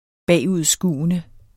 bagudskuende adjektiv Bøjning -, - Udtale [ ˈbæˀˌuðˀˌsguˀənə ] Betydninger 1. som peger bagud i tiden Synonym bagudrettet Se også tilbageskuende Rapporten er bagudskuende, så tallene er en uge til halvanden gamle.